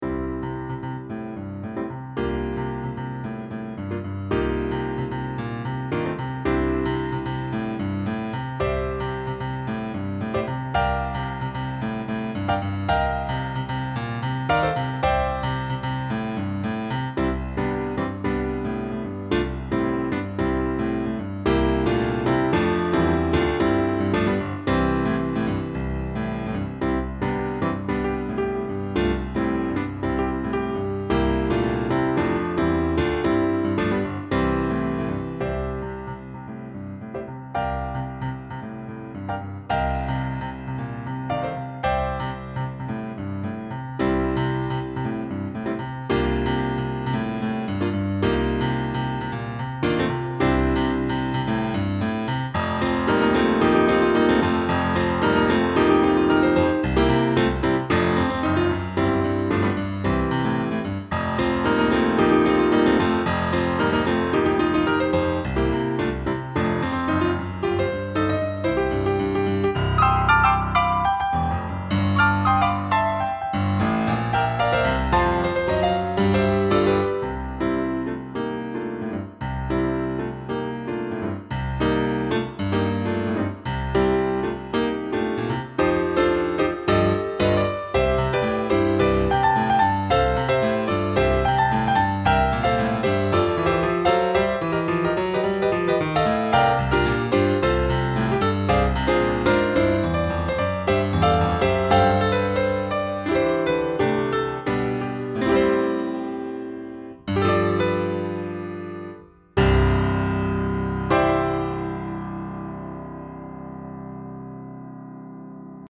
Voicing: Piano Collection